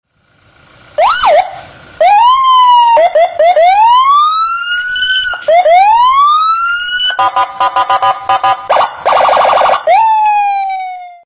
Suara sirine Polisi (Patroli)
Kategori: Nada dering
suara-sirine-polisi-patroli-id-www_tiengdong_com.mp3